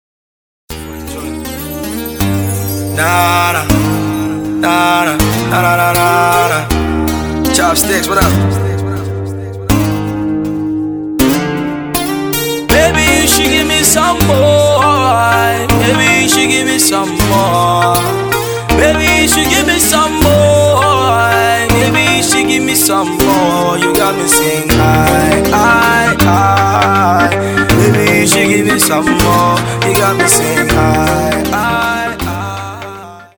somber love ballad